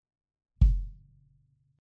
If you need more convincing here is a D6 placed outside a kick first On Axis and then aimed 90 degrees Off Axis; the gain was never adjusted.
The On Axis sound is much heavier and louder while the Off Axis is quieter and lost a lot of the boom; remember more gain to compensate will mean more hiss. The reason the Off Axis might sound clearer is because essentially we were micing the ceiling!
Off_Axis.mp3